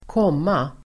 Uttal: [²k'åm:a]